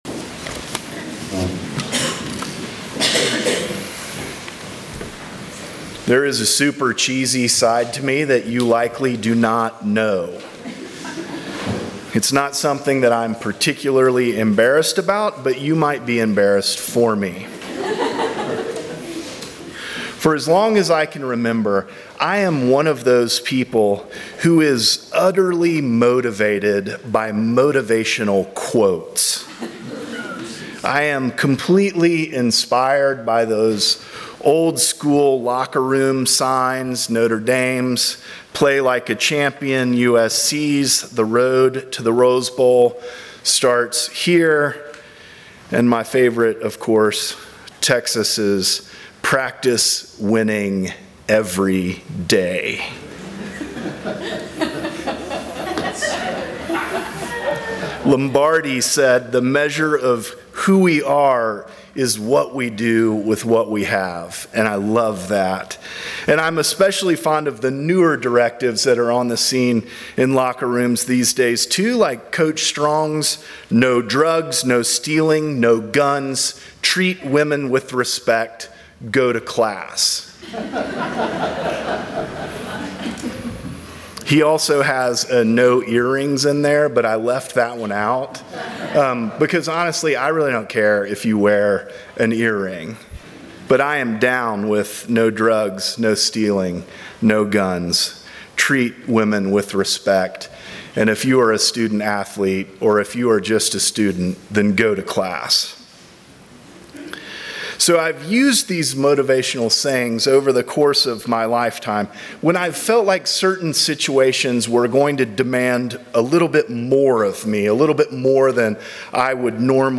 Sermons from St. John's Episcopal Church Hand to Plow